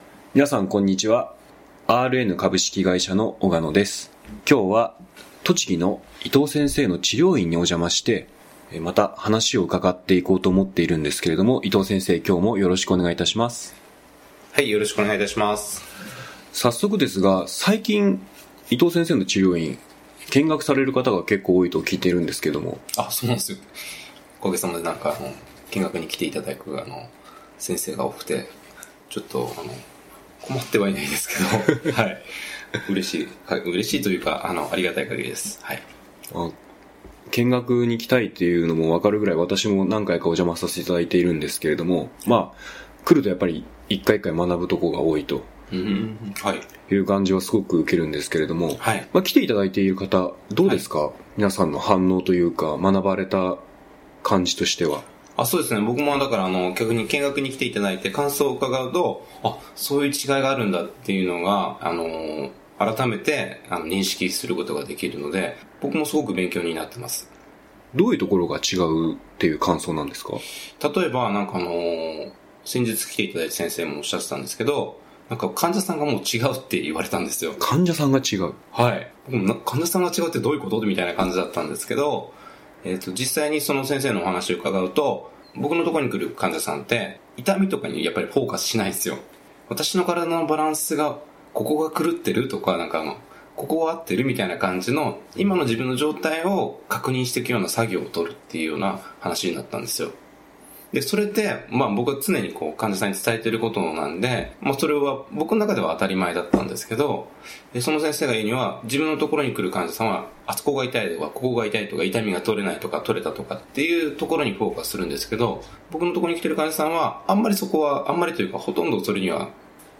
1801_scenario_taidan.mp3